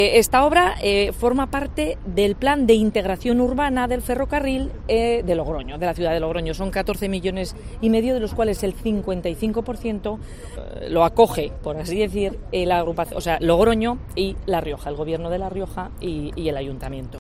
La presidenta del Gobierno de La Rioja, Concha Andreu, visita las obras de la estación